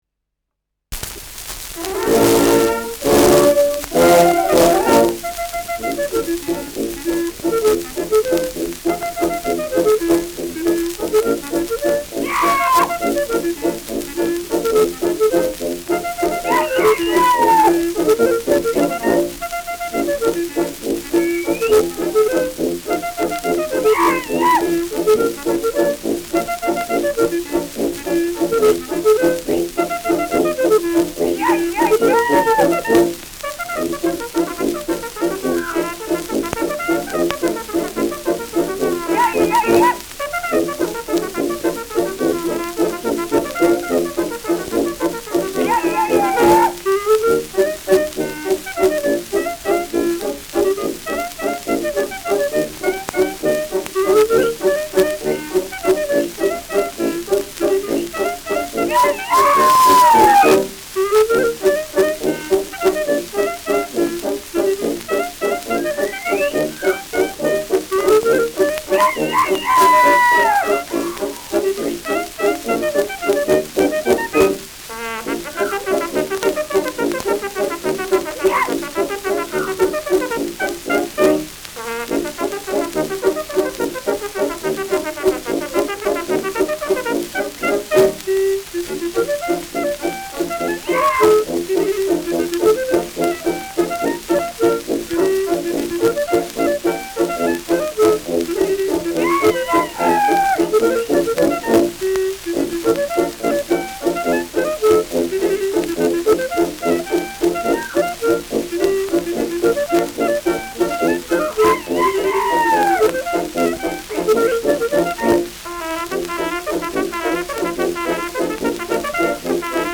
Schellackplatte
präsentes Rauschen
[Nürnberg] (Aufnahmeort)